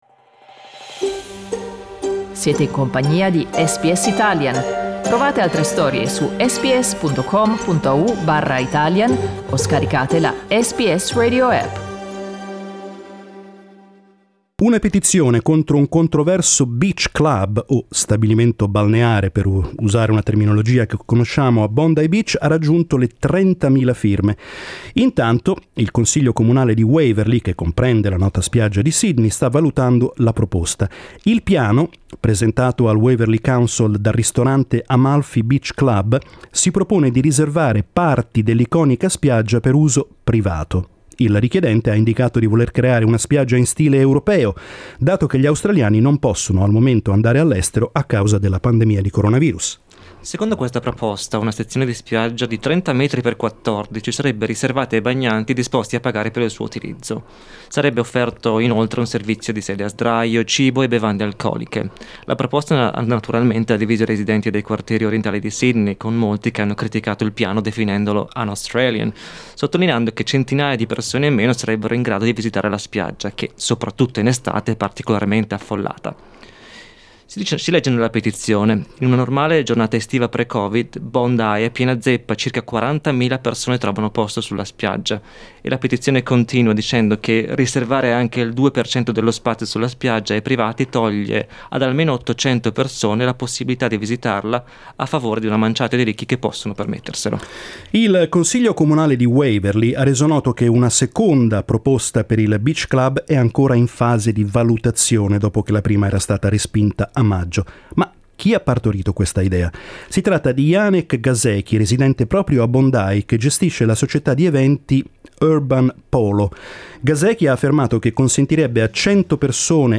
Ne abbiamo parlato oggi in diretta con i nostri ascoltatori.